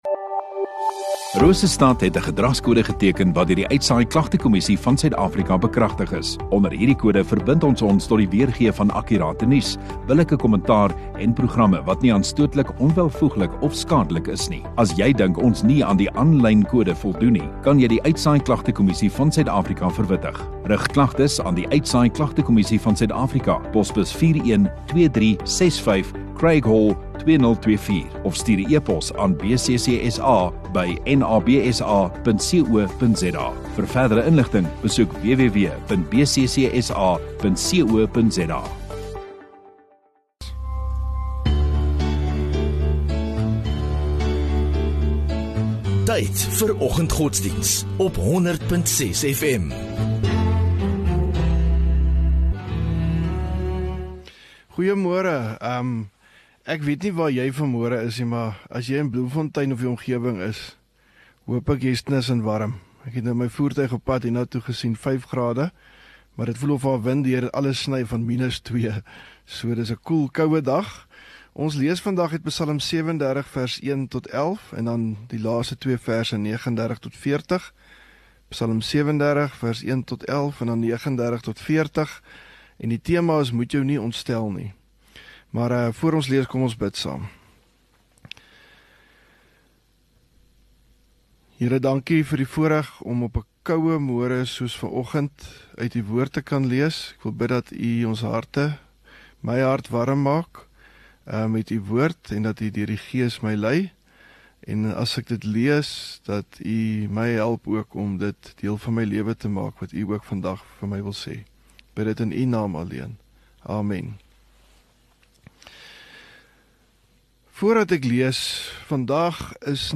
21 May Woensdag Oggenddiens